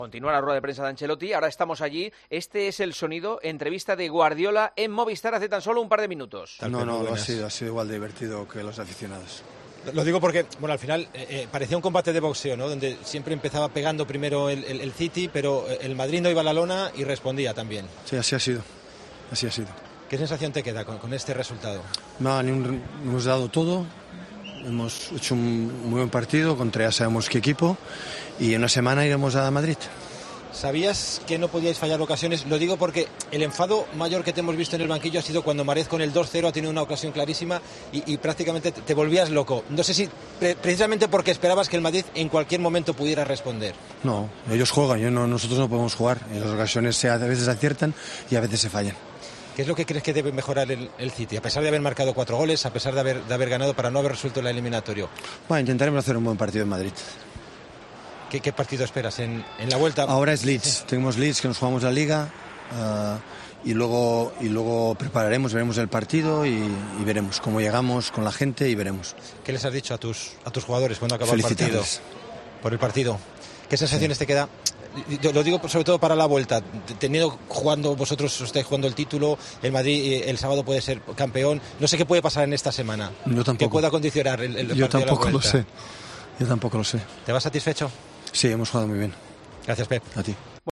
El técnico del Manchester City estuvo muy seco en su entrevista en Movistar para hablar de la victoria ante el Real Madrid en la ida de semifinales.